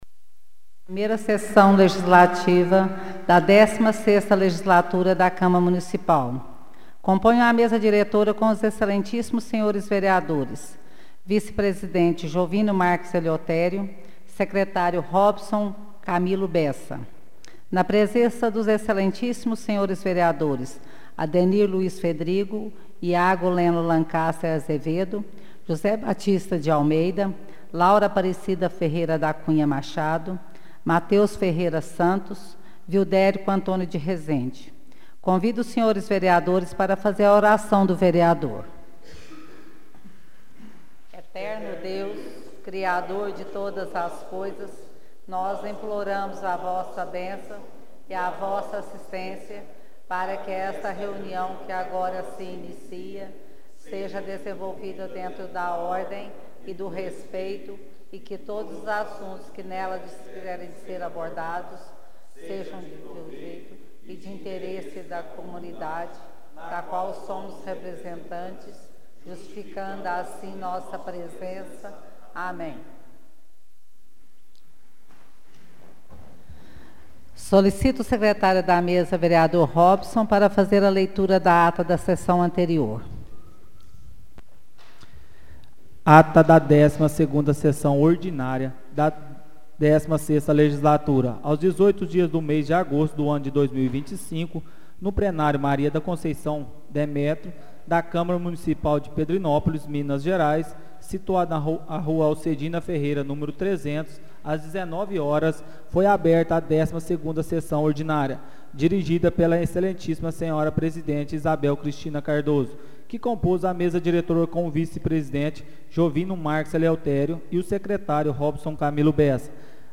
Áudio da 13ª Sessão Ordinária de 2025